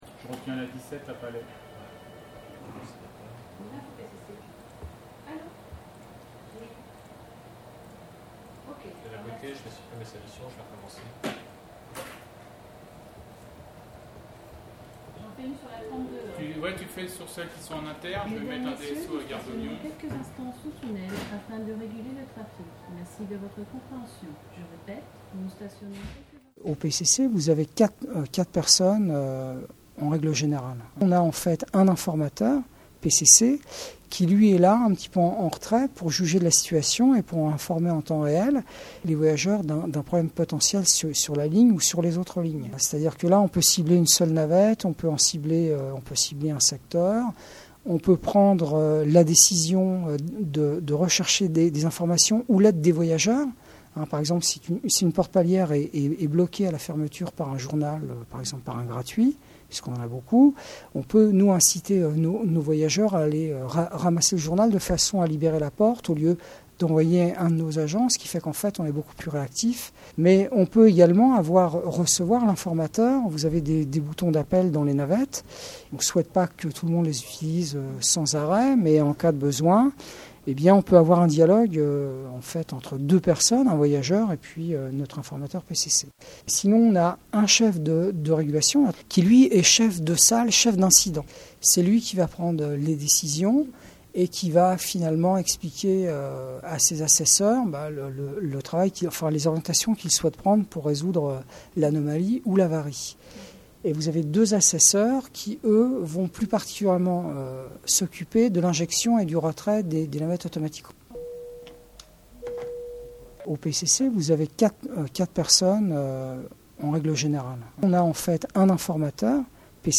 D’ordinaire plutôt silencieux, il arrive que le PCC s’anime lorsque des incidents perturbent le trafic (cliquez sur le lien pour écouter) :